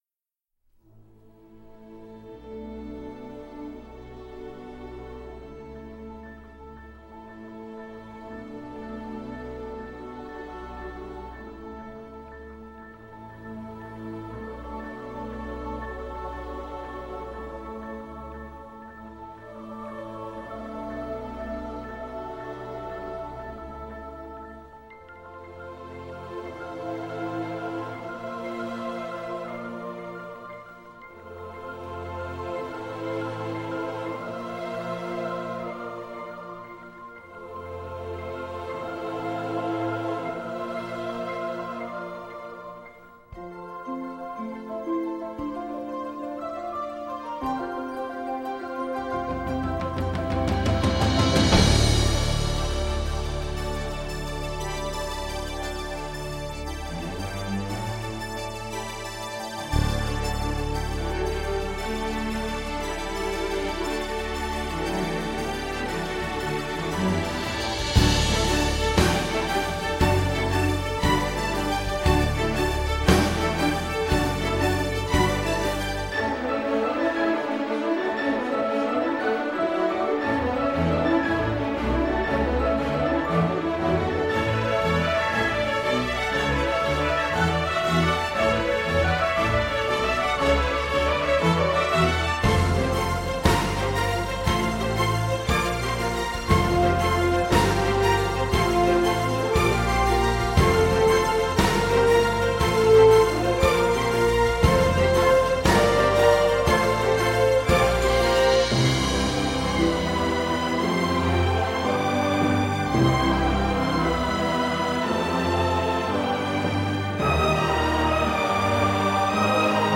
Thématique, fantasque, virevoltante, émouvante